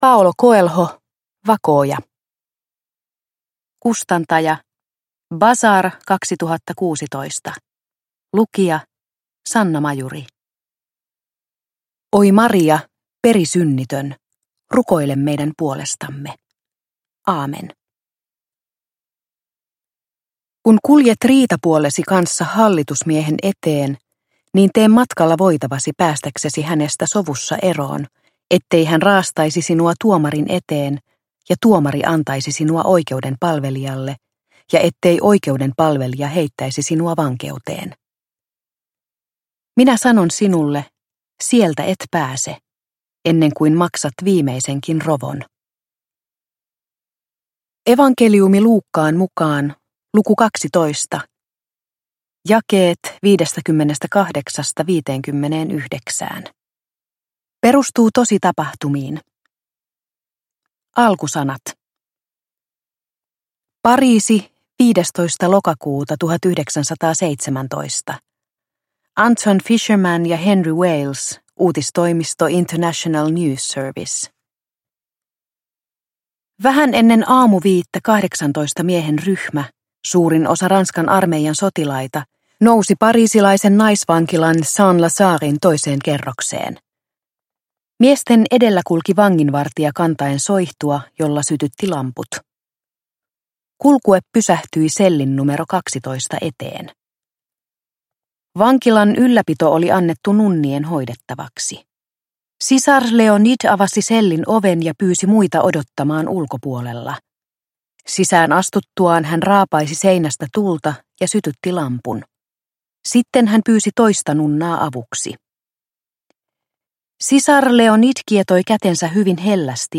Vakooja – Ljudbok